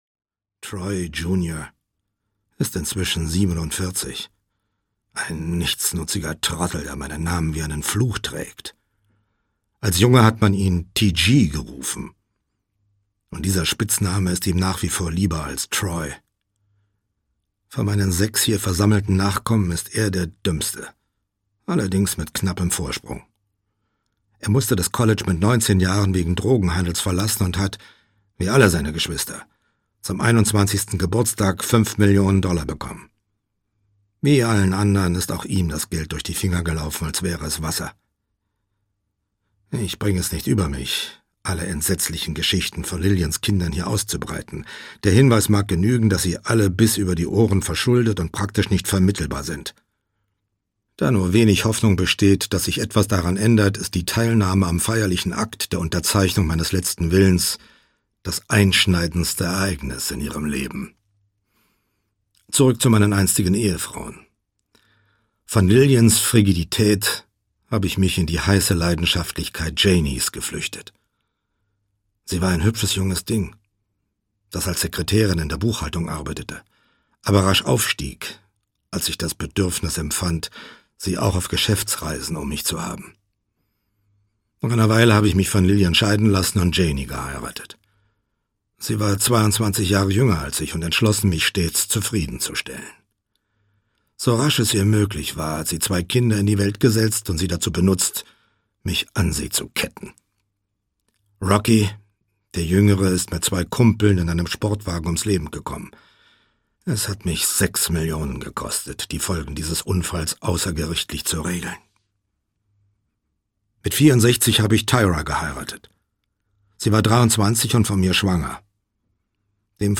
Audio kniha
• InterpretCharles Brauer